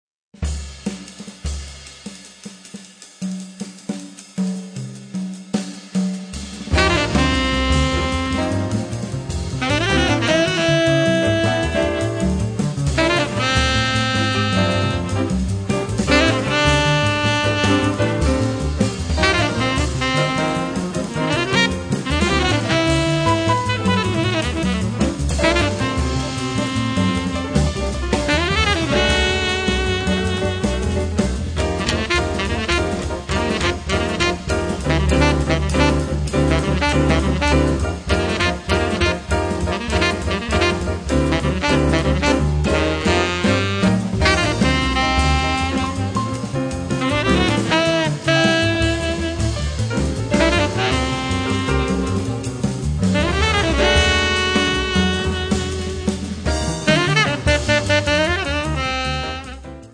piano, hammond
tenorsax
contrabbasso
batteria